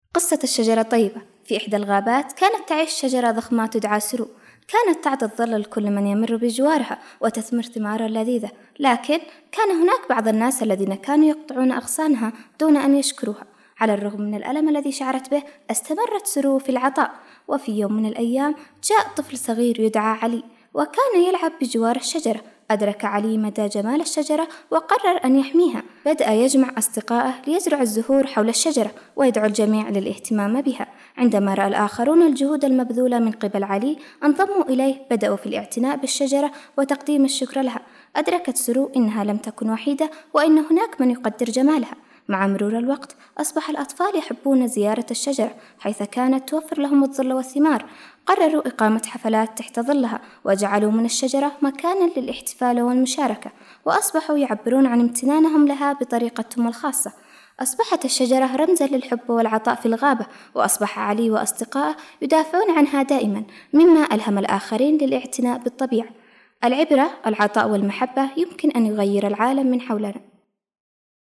قصة قصيرة